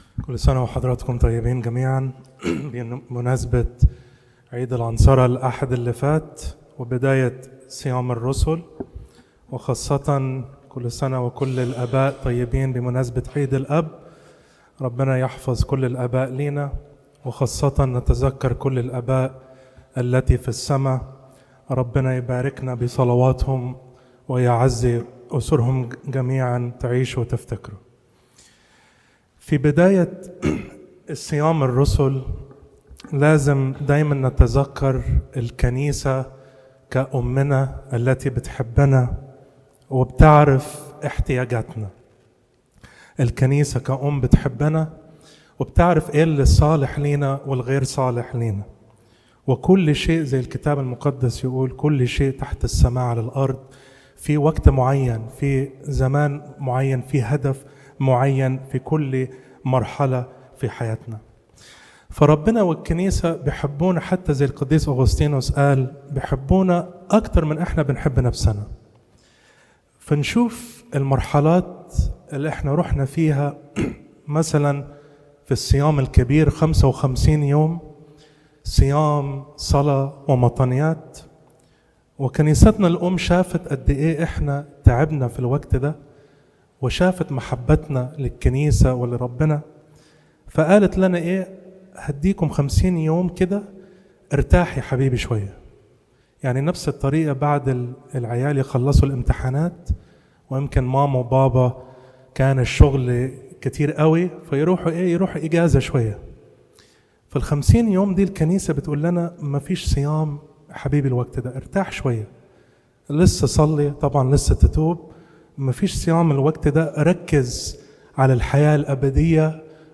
Content Type: Sermon